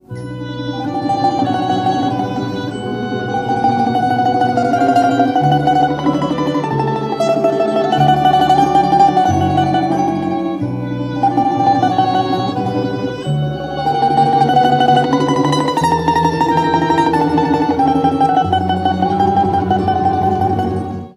Откровенная малая секунда на сильную долю, в одной октаве.. чем объяснить?